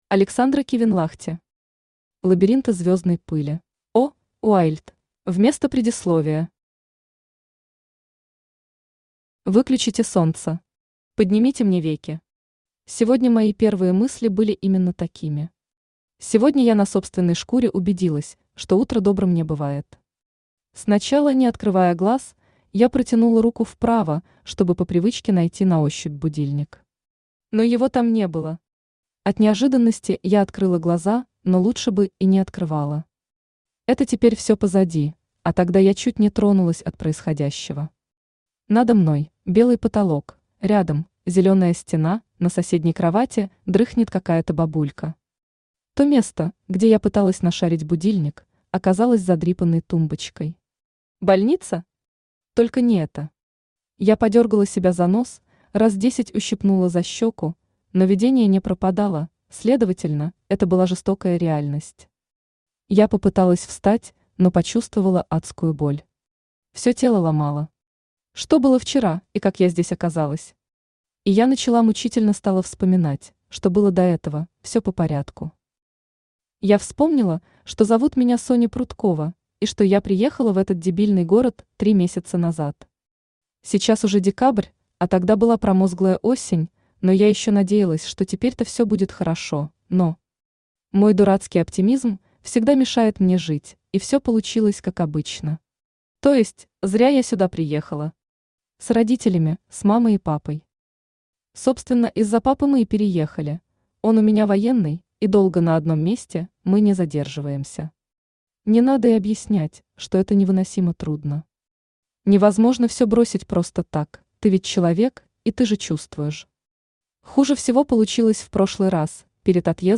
Аудиокнига Лабиринты звездной пыли | Библиотека аудиокниг
Aудиокнига Лабиринты звездной пыли Автор Александра Кивенлахти Читает аудиокнигу Авточтец ЛитРес.